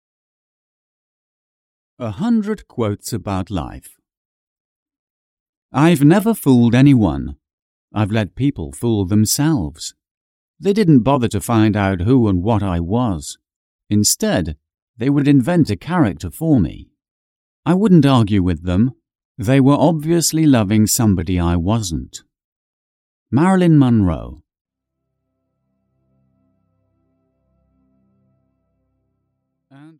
100 Life Lesson Quotes (EN) audiokniha
Ukázka z knihy